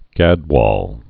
(gădwôl)